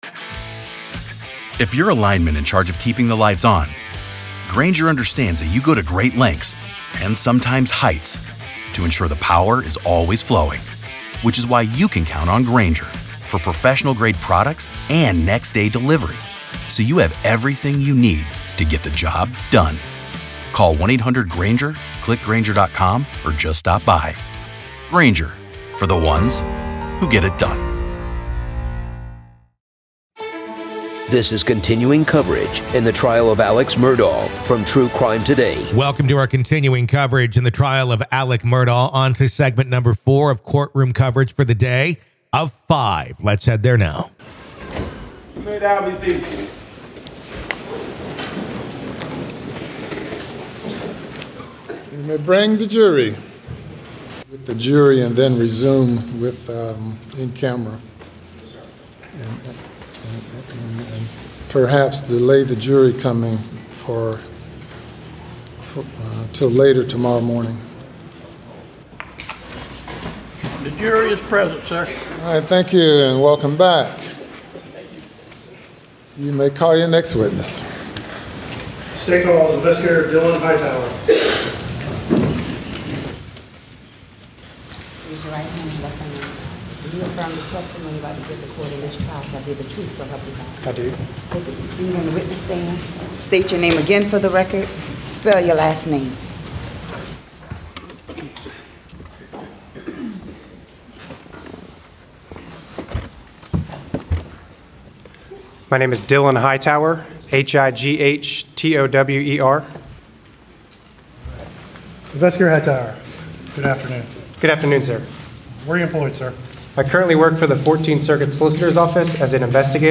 Alex Murdaugh Trial: Courtroom Coverage | Day 7, Part 4